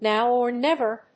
アクセント(It's) nów or néver!